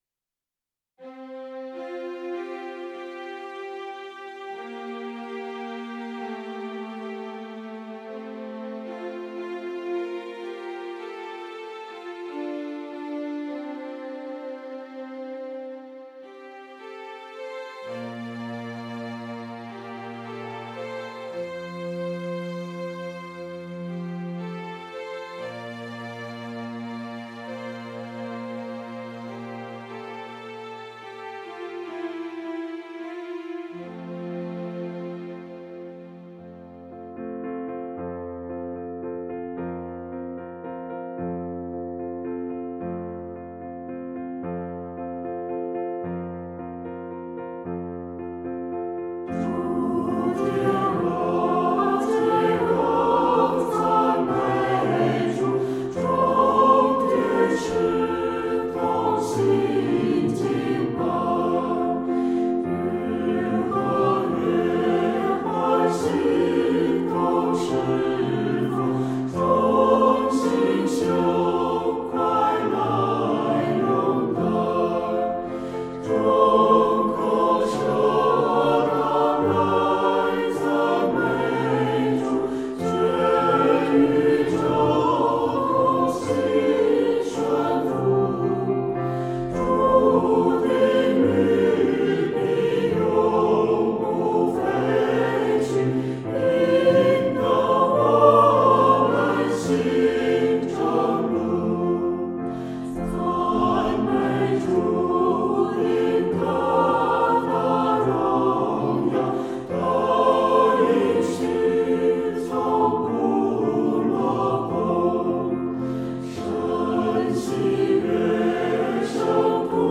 唱诗：诸天赞美（新27）